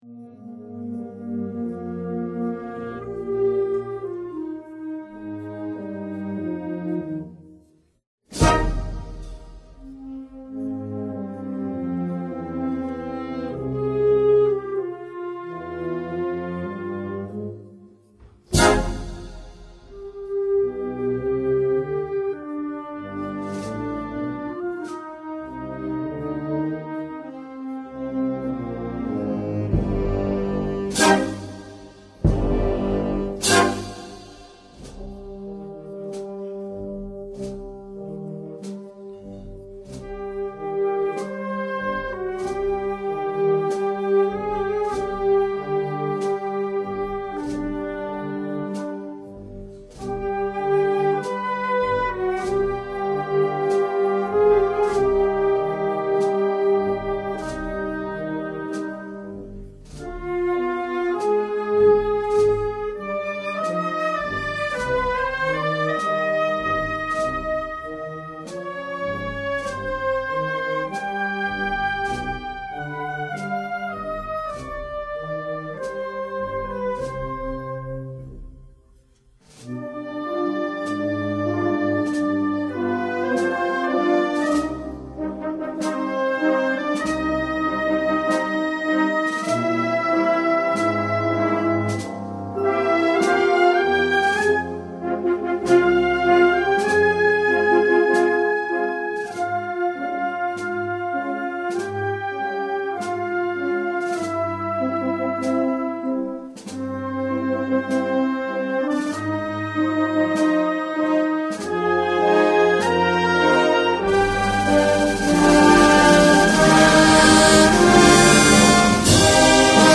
Estamos ante una marcha fúnebre que afortunadamente se ha recuperado en la Semana Santa de Cádiz, ya que hasta hace algunos años apenas se interpretaba.
Tiene esta pieza una sensibilidad exquisita e irrepetible.
en el Concierto de Cuaresma